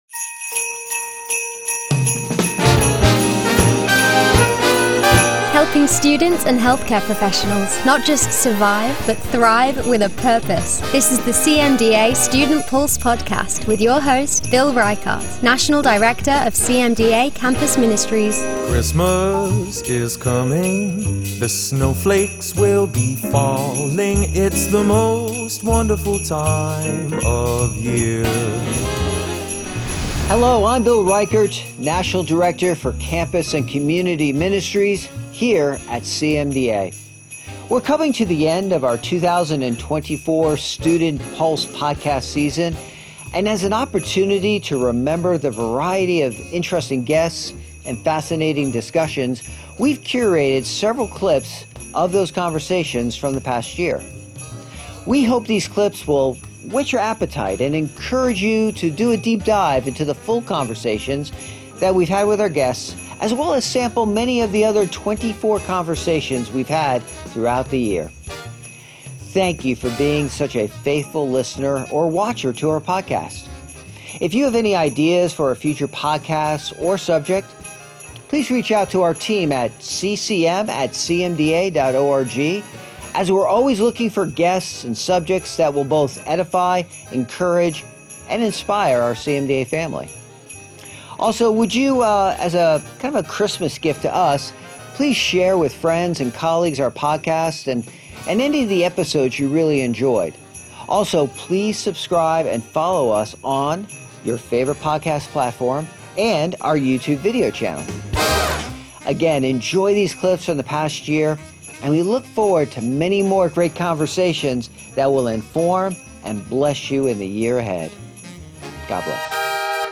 We're coming to the end of our 2024 Student Pulse podcast season. And as an opportunity to remember the variety of interesting guests and fascinating discussions, we've curated several clips of those conversations from the past year.